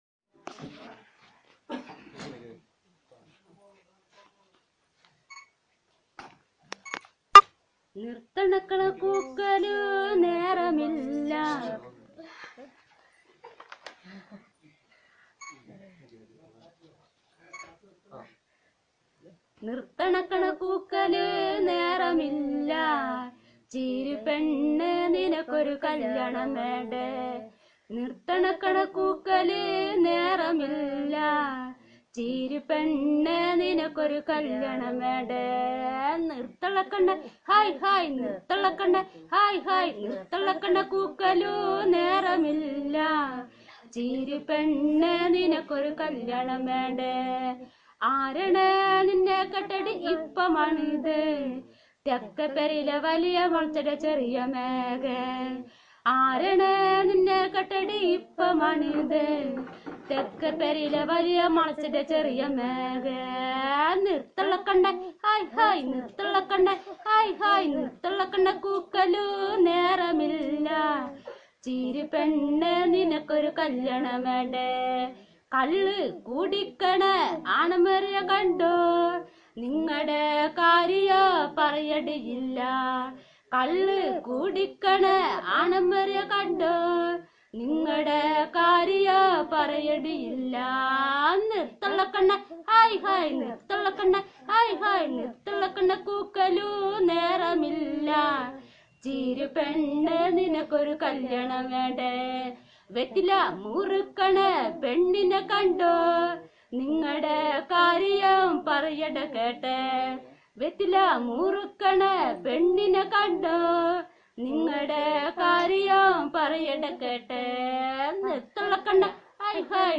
Performance of folk song